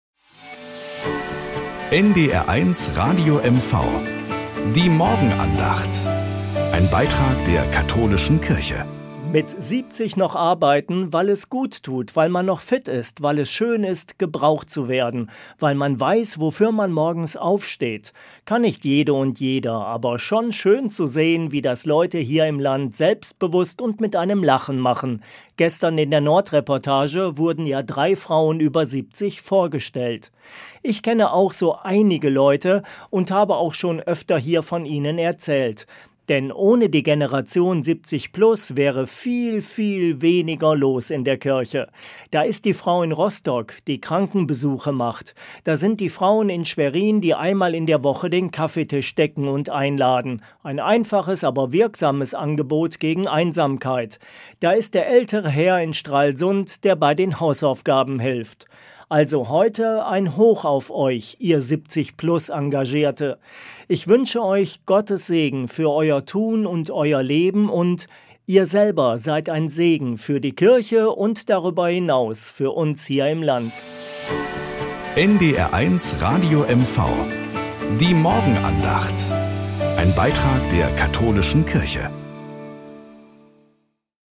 Morgenandacht.